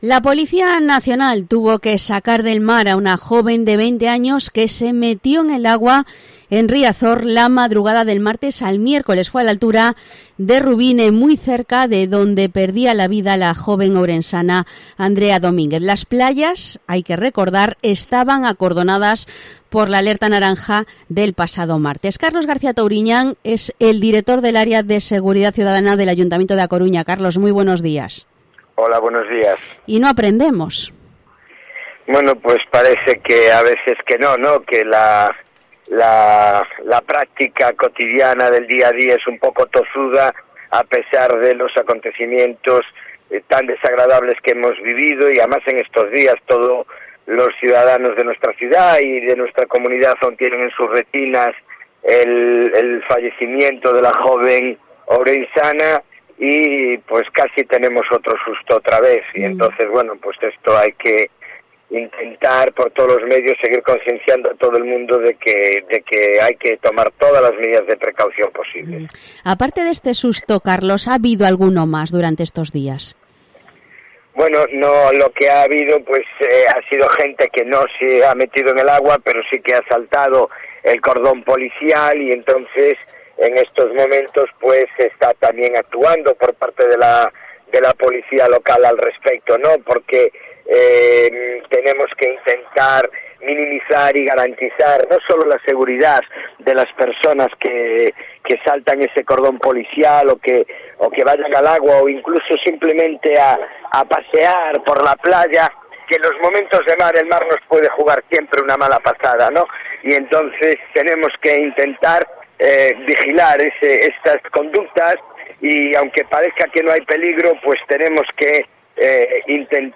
Entrevista a Carlos García Touriñán, director de Seguridad Ciudadana de A Coruña